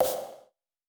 TC PERC 10.wav